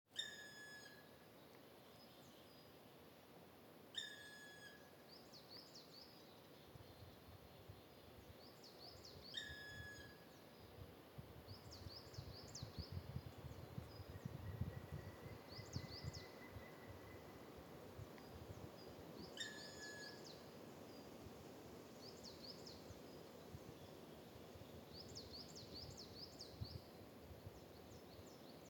7. Yellow-bellied Sapsucker (Sphyrapicus varius)
Call: Nasal “mew” and irregular drumming.